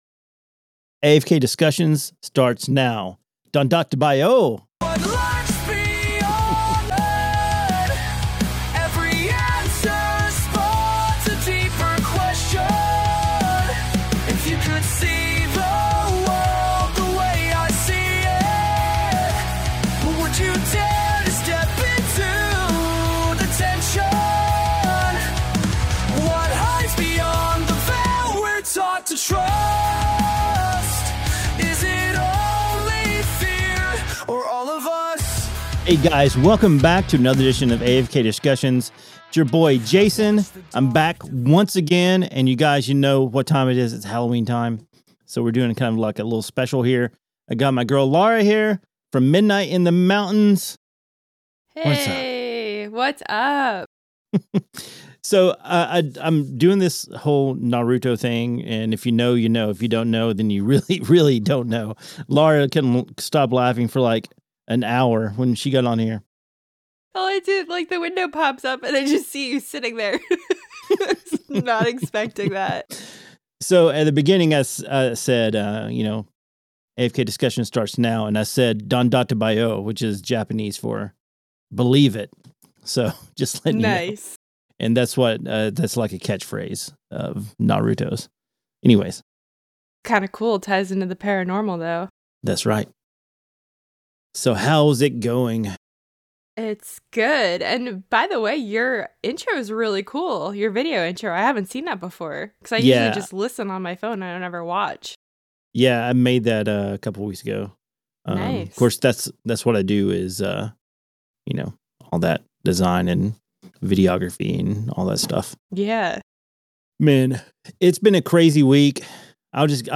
They explore the fascinating world of vampire mythology, particularly focusing on Asian vampires and the unique characteristics of Yokai from Japanese folklore. The conversation shifts to the historical perspectives on vampires, discussing their origins and the various legends that have emerged over time. The episode is filled with humor, personal anecdotes, and intriguing insights into the supernatural.